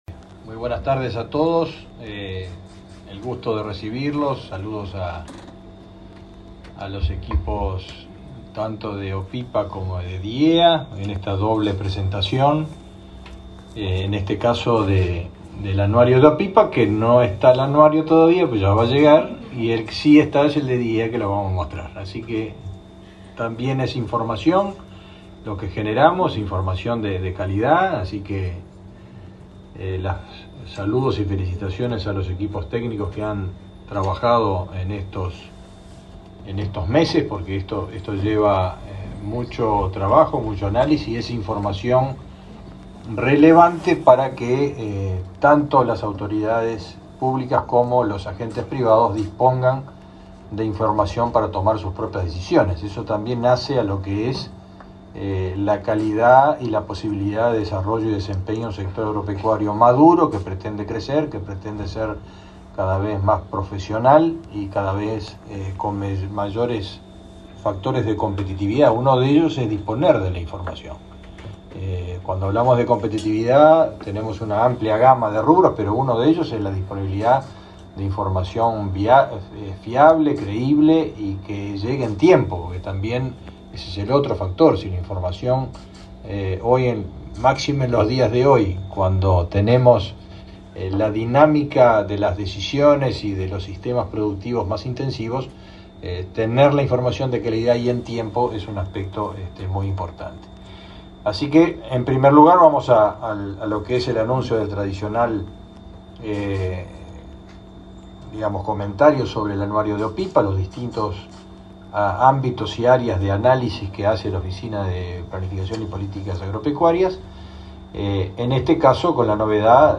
Palabra de autoridades del MGAP